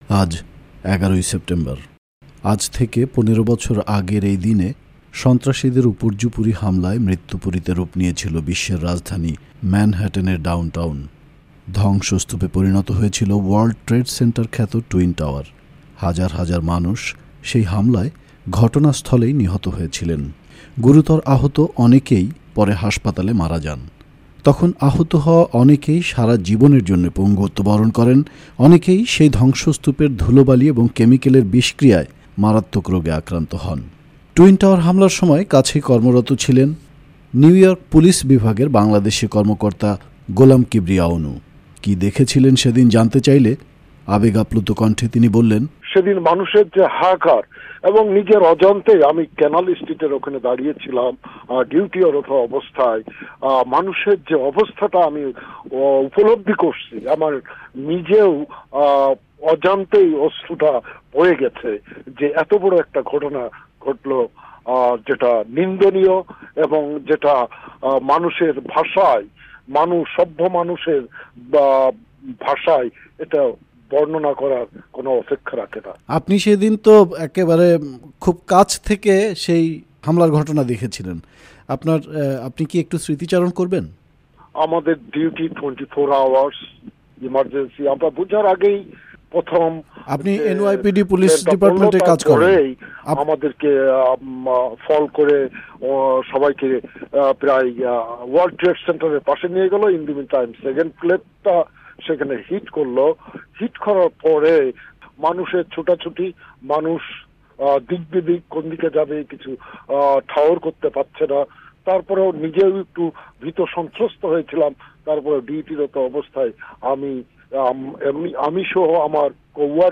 পনেরো বছর আগের সেই ভয়াবহ ঘটনার একজন প্রত্যক্ষদর্শী ও স্বেচ্ছাসেবী বাংলাদেশী আমেরিকান